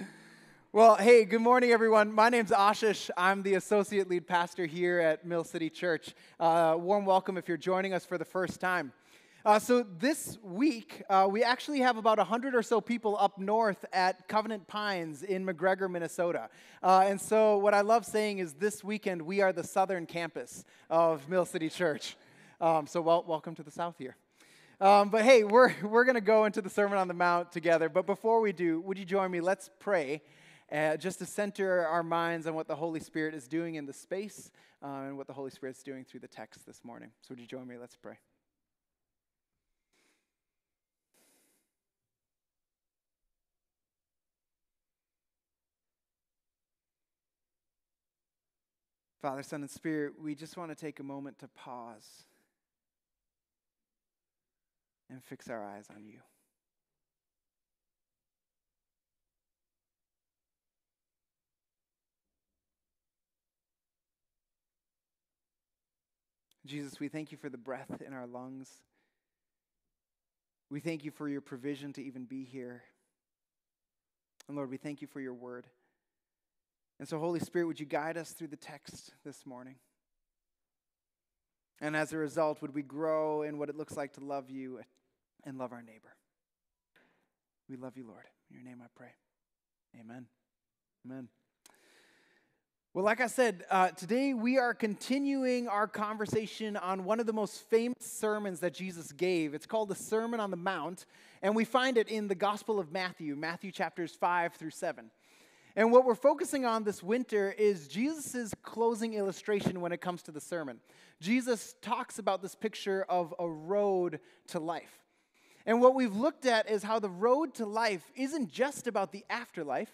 Mill City Church Sermons The Road to Life: Seek First the Kingdom Jan 28 2025 | 00:31:35 1x 00:00 / 00:31:35 Subscribe Share RSS Feed Share Link Embed